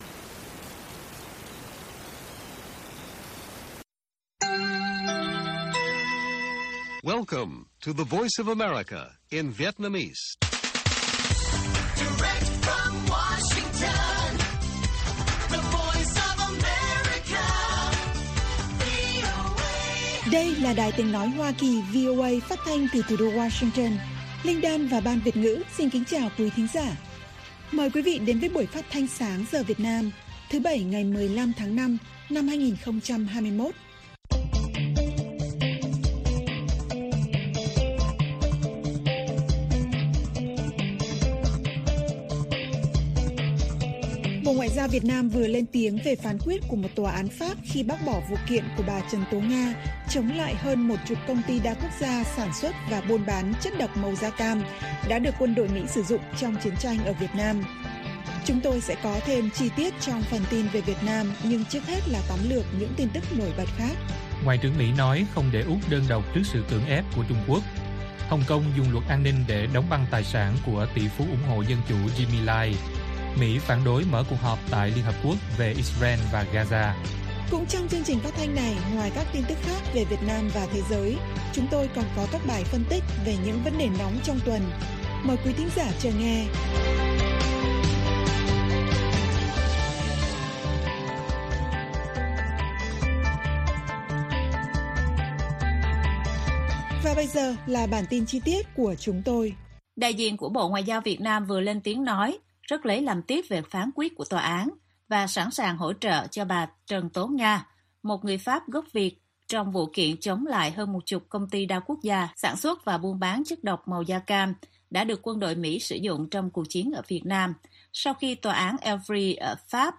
Bản tin VOA ngày 15/5/2021